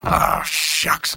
Robot-filtered lines from MvM. This is an audio clip from the game Team Fortress 2 .
Engineer_mvm_negativevocalization02.mp3